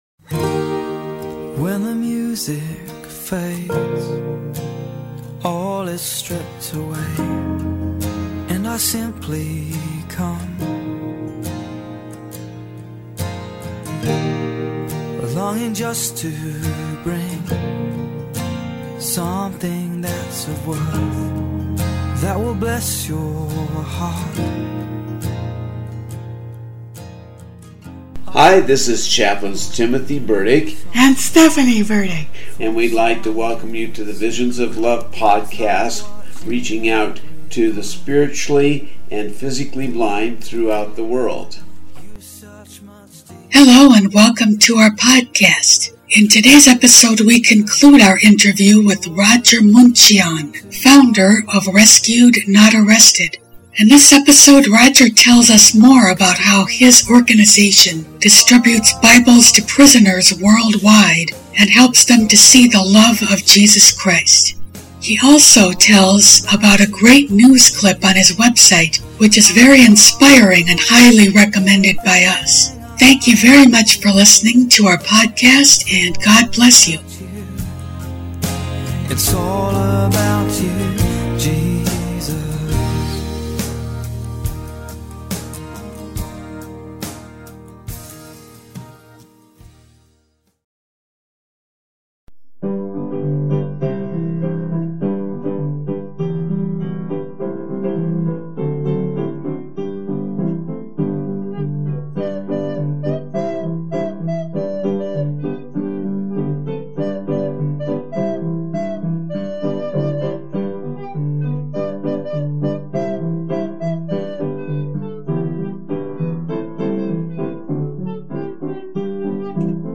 Enjoy his inspiring story, and the music which precedes and follows it.